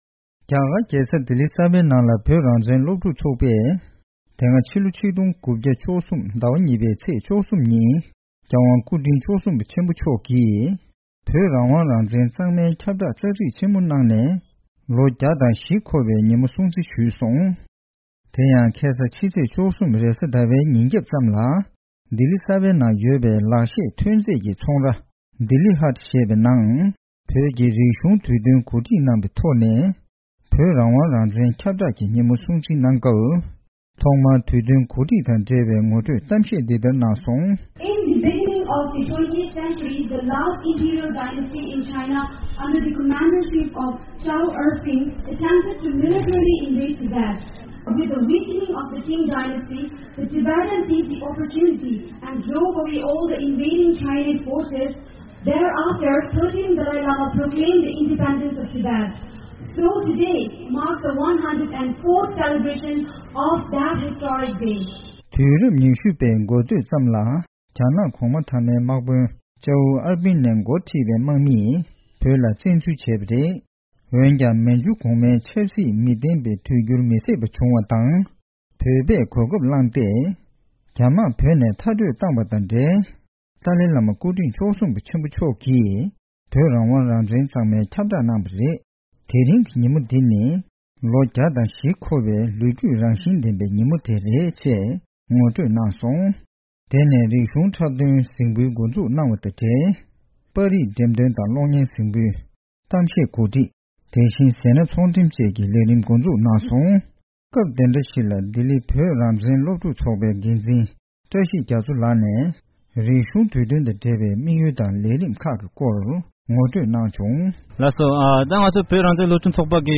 To mark the 104th anniversary of the 1913 Tibetan Proclamation of Independence from China, Students for a Free Tibet - Delhi (SFT-Delhi) organized "Tibetan Cultural Festival" in Dilli Haat, New Delhi on February 13, 2017.